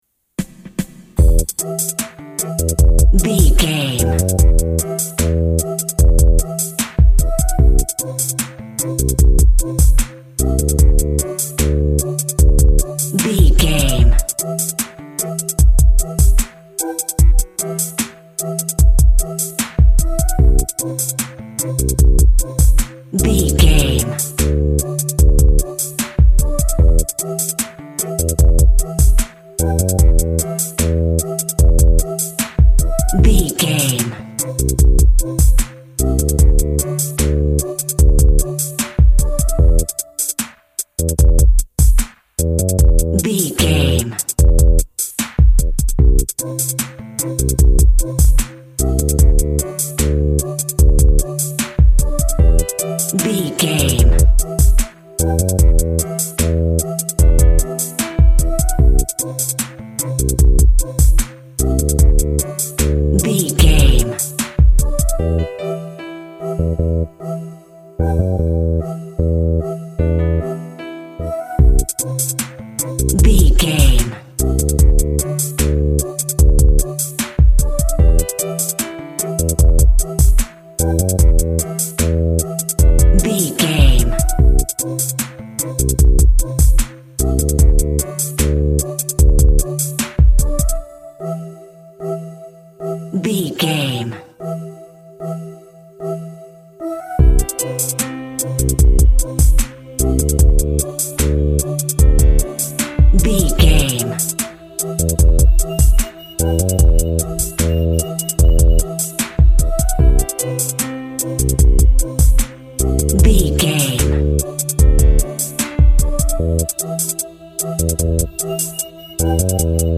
Aeolian/Minor
hip hop
rap music
synth lead
synth bass
hip hop synths
electronics